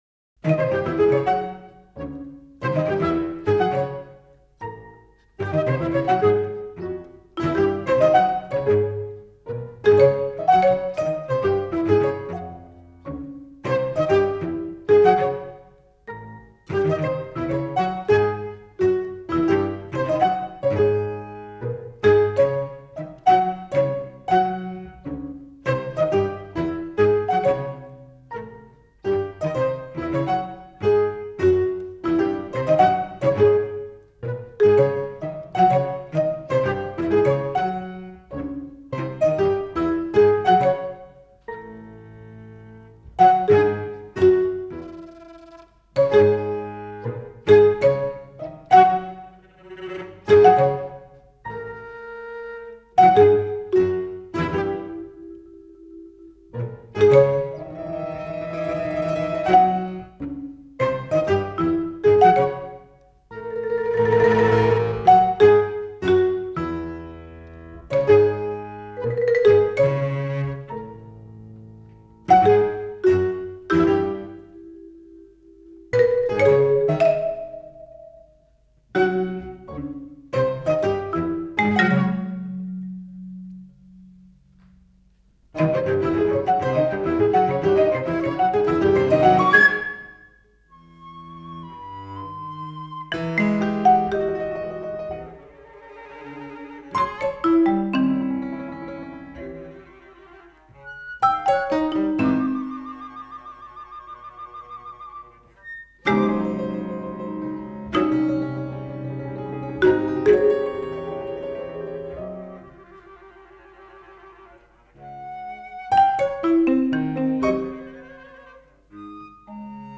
Cuarterola para flauta, cello, marimba y piano